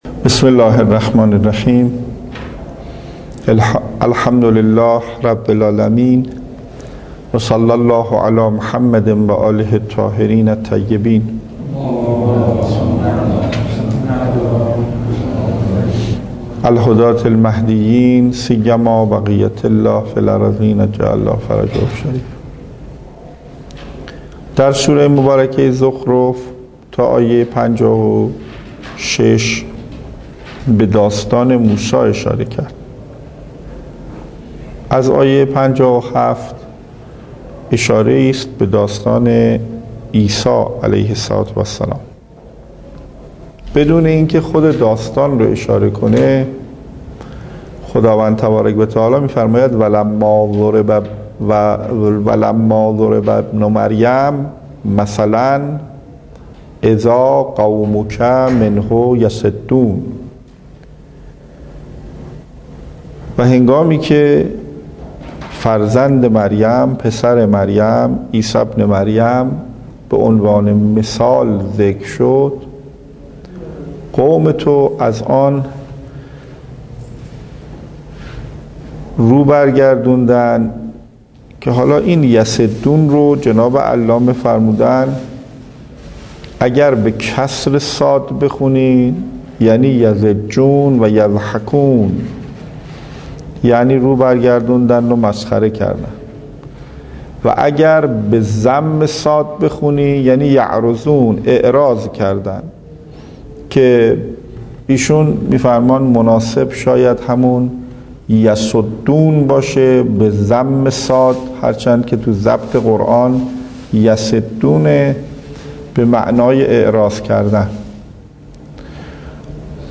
سی و ششمین جلسه تفسیر سوره زخرف